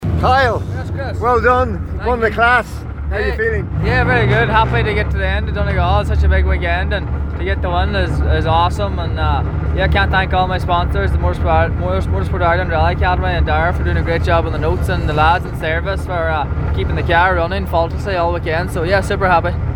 Top competitors react to enthralling Donegal International Rally – Finish-line chats